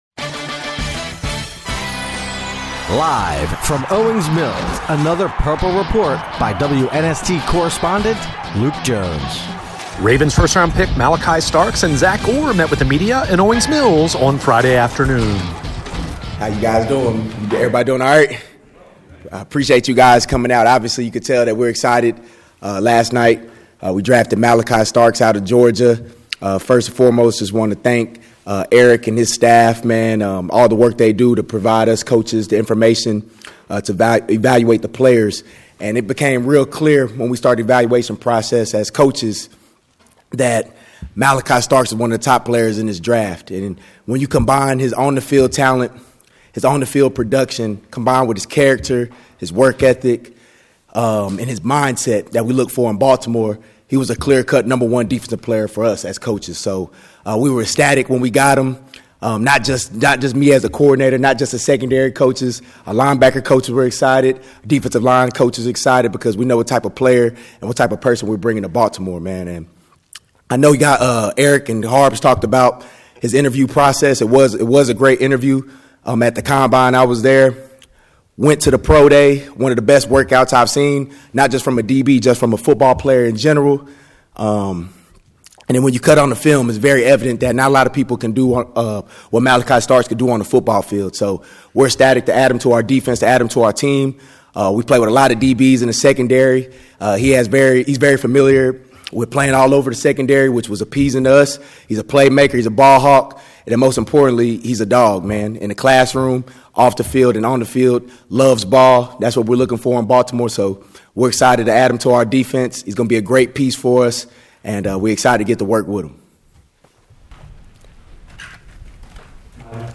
New Ravens safety Malaki Starks and defensive coordinator Zach Orr meet with media in Owings Mills